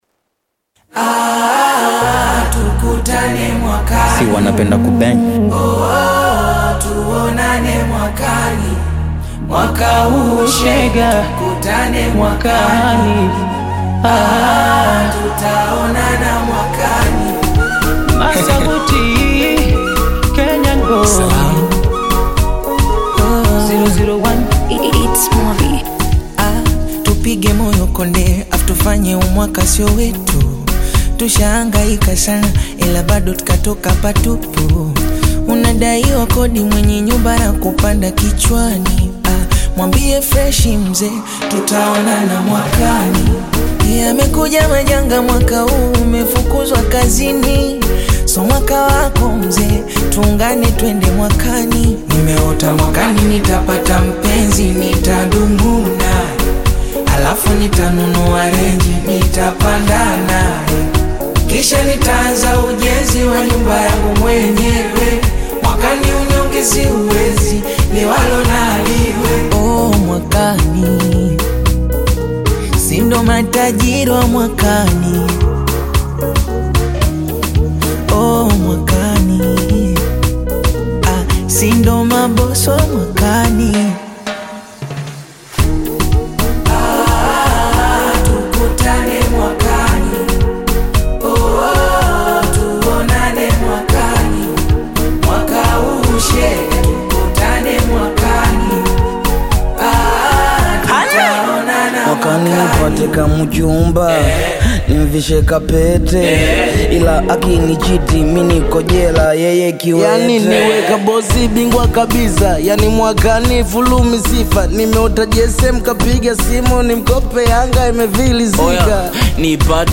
upbeat Afrobeats/Bongo Flava single